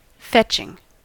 fetching: Wikimedia Commons US English Pronunciations
En-us-fetching.WAV